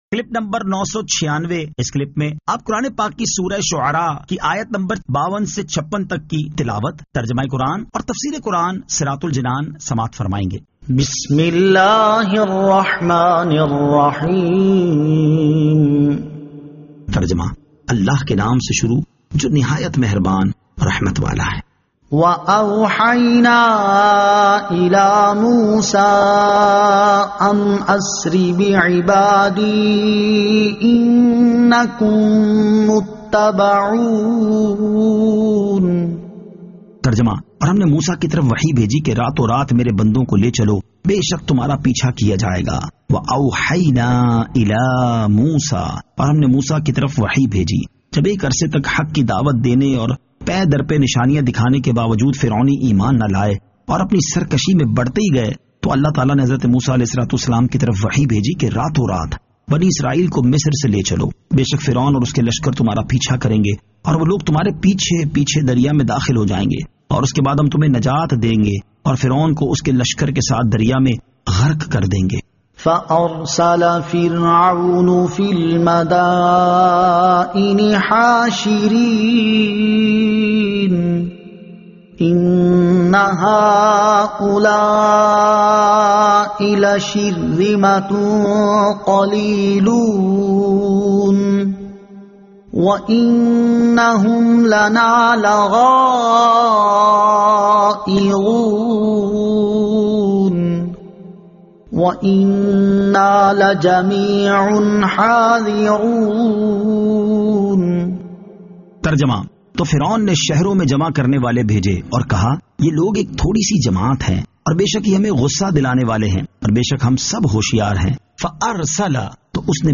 Surah Ash-Shu'ara 52 To 56 Tilawat , Tarjama , Tafseer